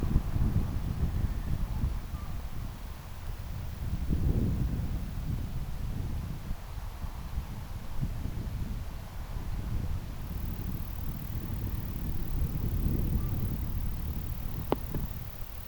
kaksi hiljaista kuin kyläpöllösen ääntä
kuuluu kauempaa
kaksi_hiljaista_ehka_kylapollosen_aanta.mp3